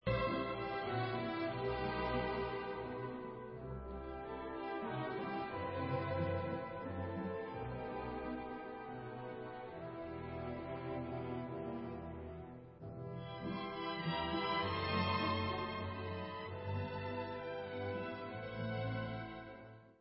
D dur (Allegretto scherzando) /Sousedská